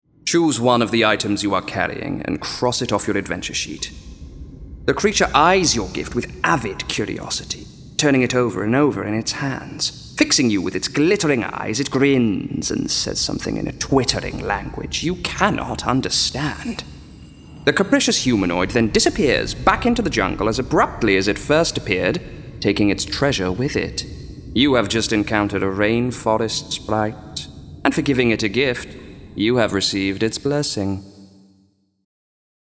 • Male
Fantasy Narration Audio Book. Characters, Conversational
5.-Audio-Book-2-Fantasy-Narration.mp3